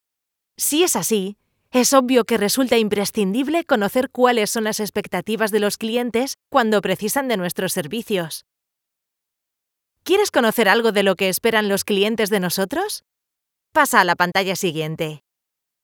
My voz es cálida, cercana, amistosa, próxima, y joven.
My voice is warm, friendly, closer, and young.
Sprechprobe: eLearning (Muttersprache):